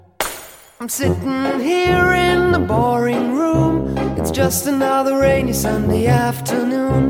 常常略读，舌头和口型到位，但是不发音。